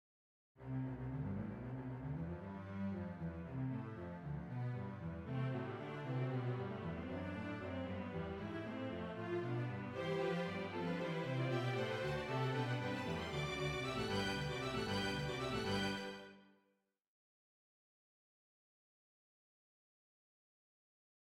On retrouve cet esprit dans le scherzo de la 5eme symphonie, alors que les basses entonnent ce passage endiablé:
scherzo-5eme-1.mp3